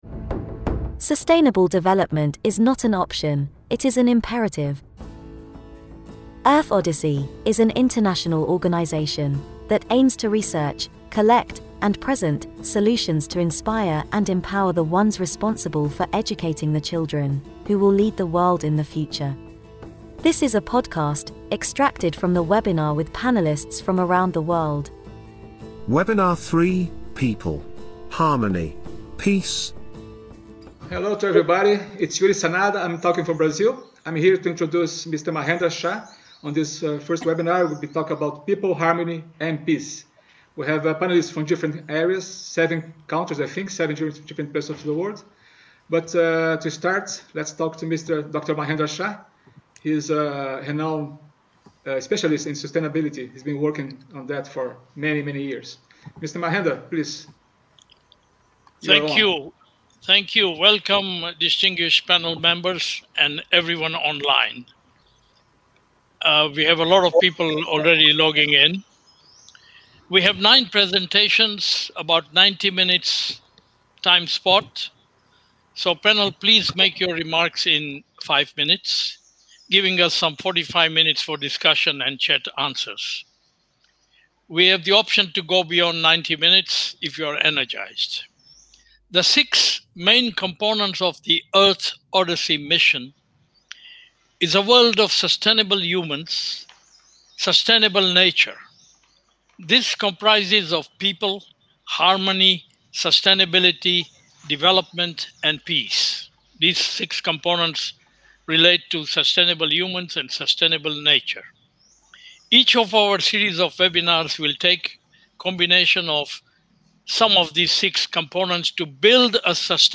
WEBINAR3.mp3